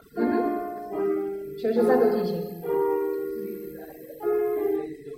之前全是三度进行：